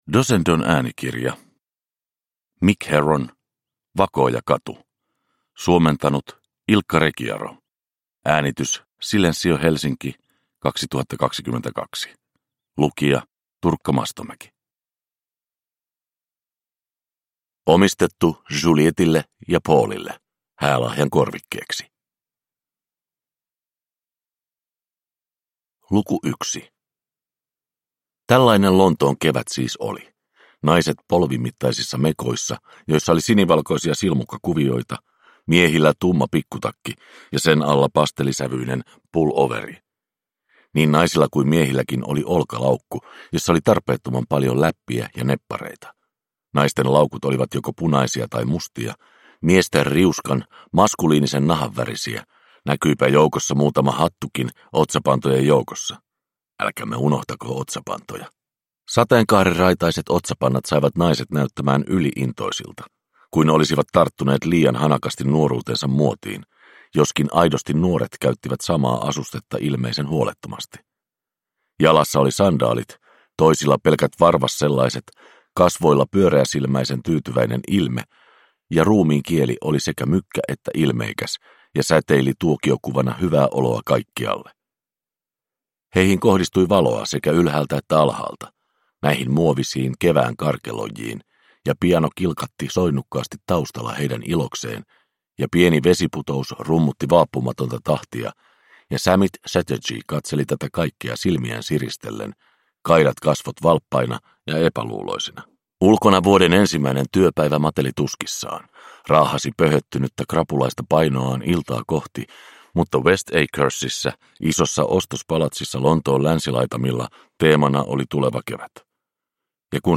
Vakoojakatu – Ljudbok – Laddas ner